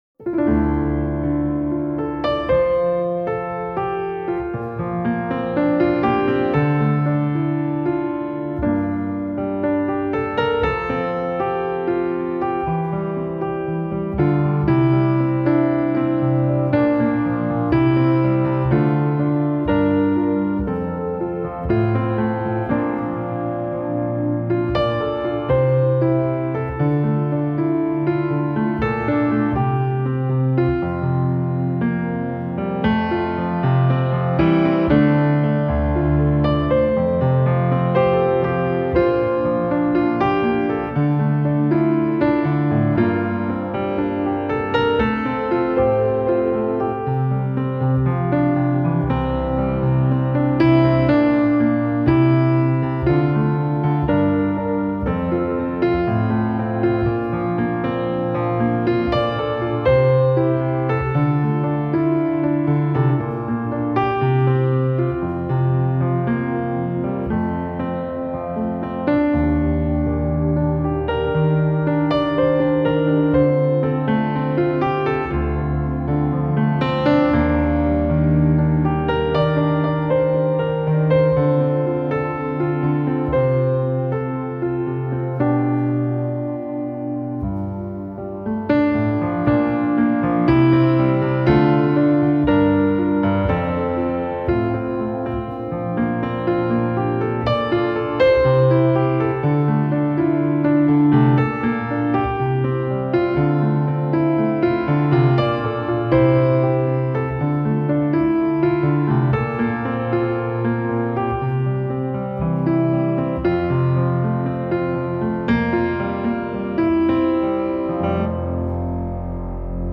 这是一张清纯、温馨、自然的专辑，找到重新令人精神振奋的感觉。